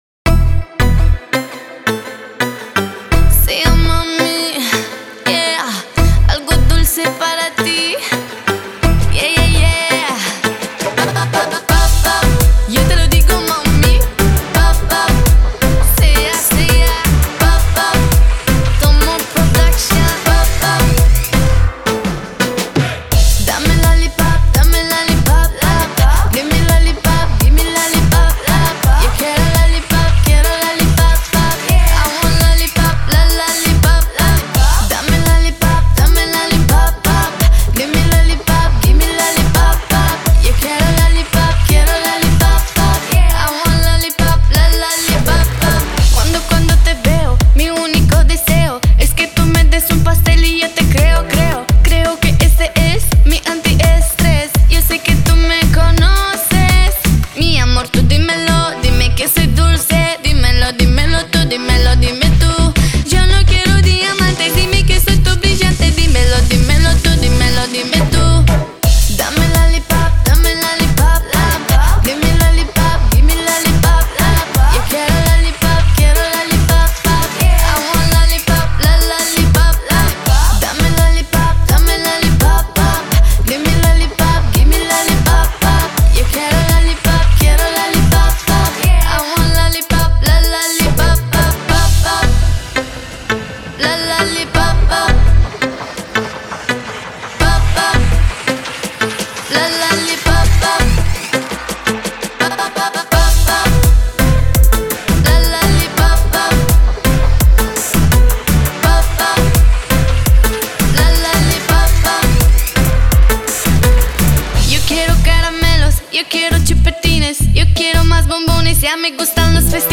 • Жанр: Русские песни